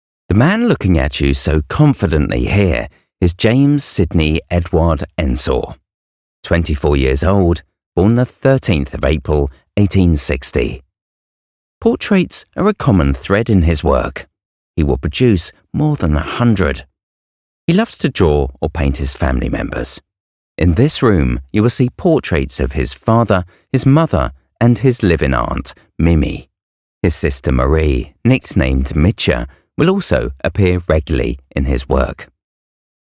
Anglais (britannique)
Audioguides
Neumann TLM-103
Cabine insonorisée
Âge moyen
Baryton